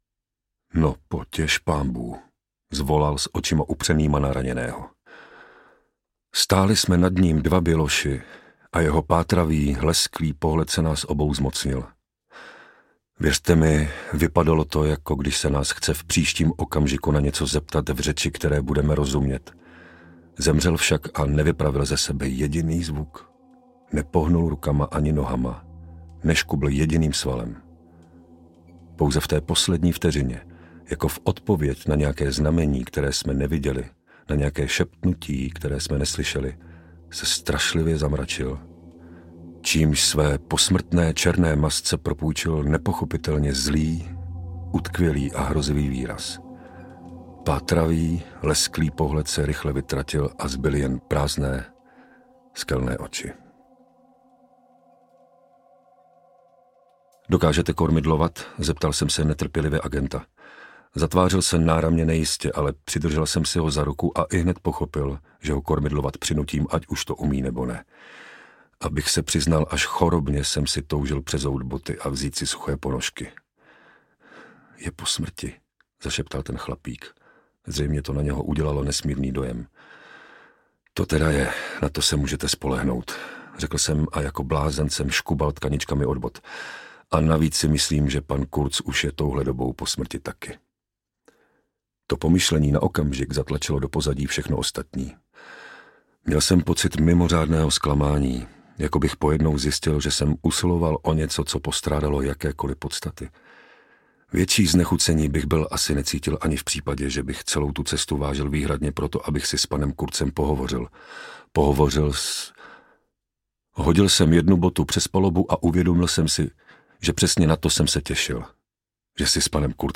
Srdce temnoty audiokniha
Ukázka z knihy
| Vyrobilo studio Soundguru.